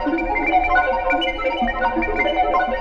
RI_ArpegiFex_85-04.wav